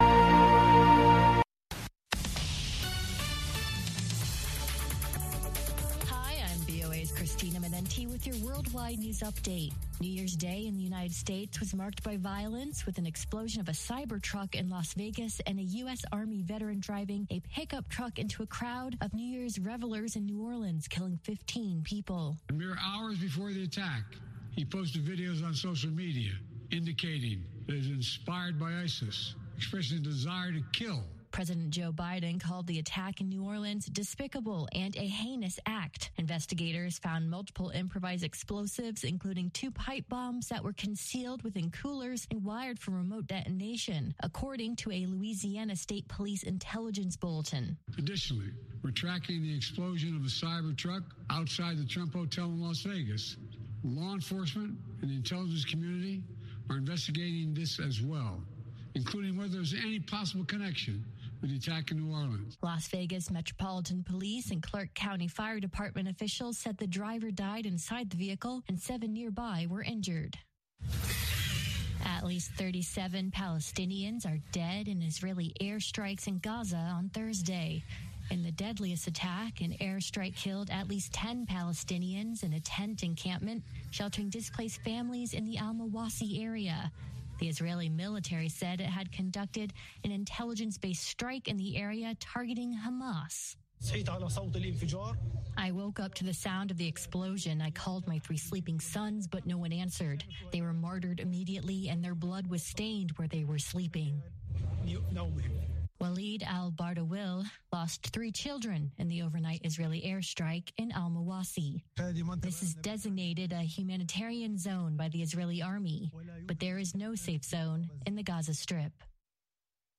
radio show
delivers an upbeat and lively presentation during his 30 minute sports shows